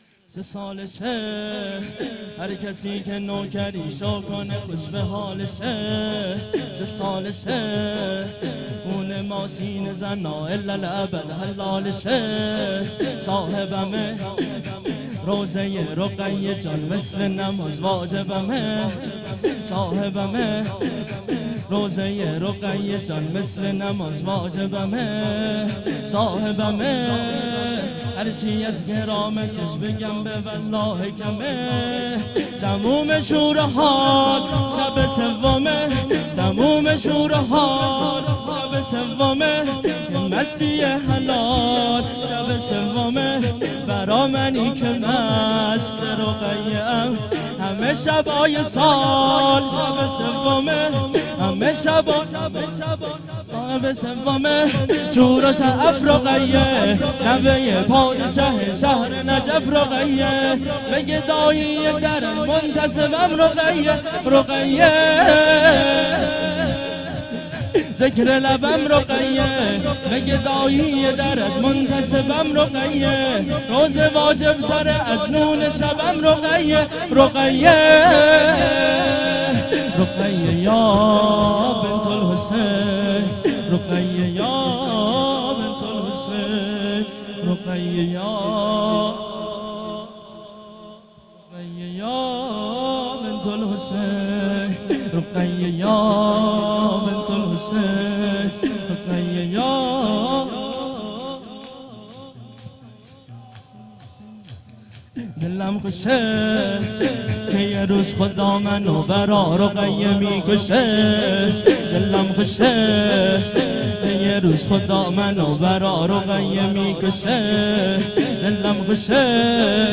شور